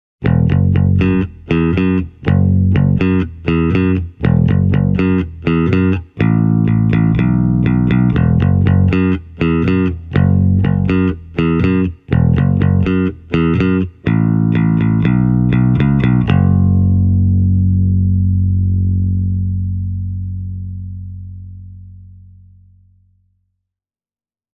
All clips have been recorded with a microphone:
Jazz Bass – light bass boost, Tweeter-switch set to Dim